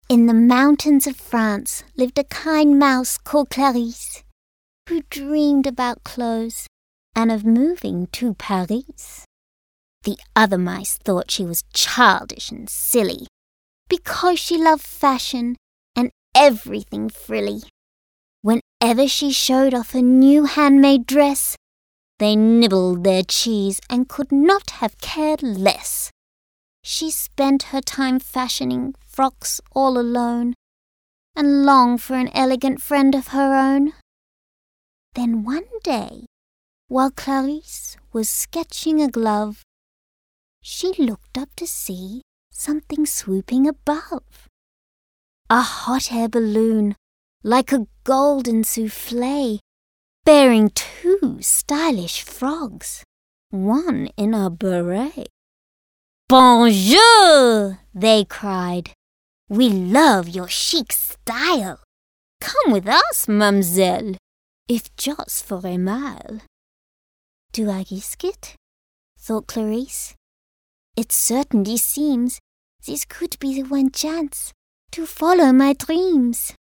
Female
English (Australian)
Child (0-12), Teenager (13-17)
It is childlike, quirky and otherworldly.
I am very emotive and have a natural theatrical flair.
Audiobooks